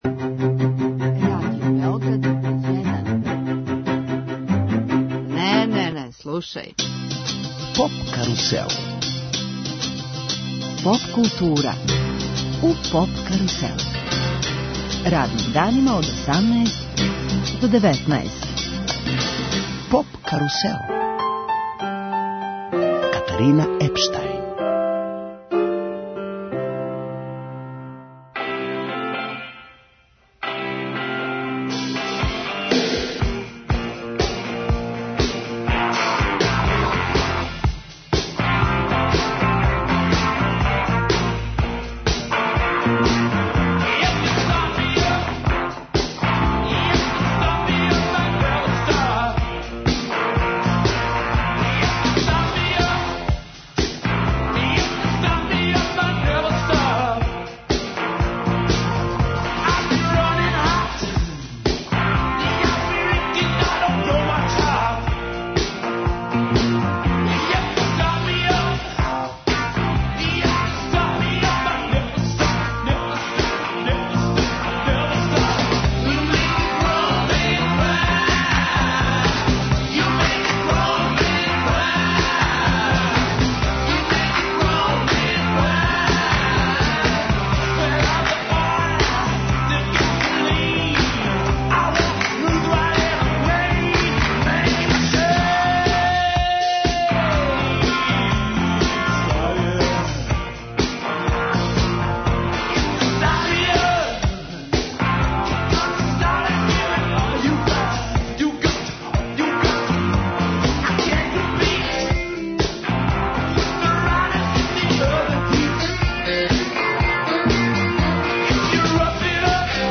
Гости емисије су чланови састава Atheist Rap.